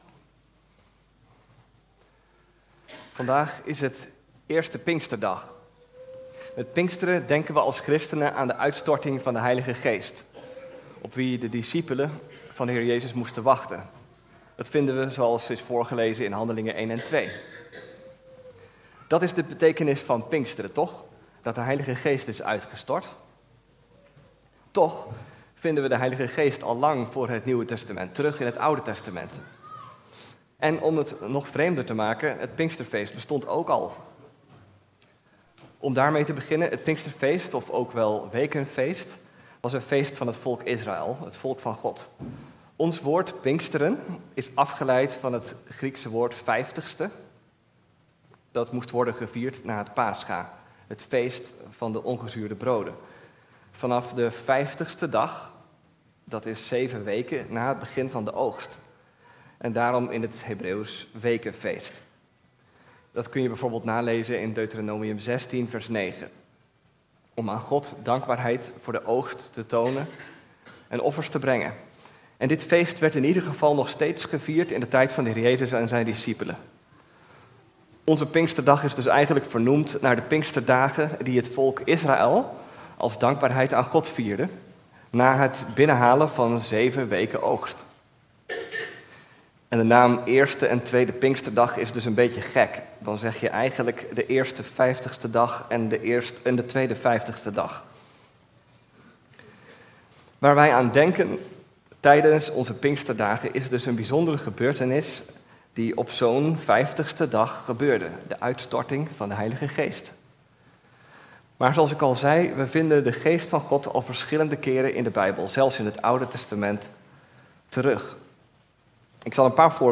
Toespraak 5 juni: Wat veranderde er op de Pinksterdag?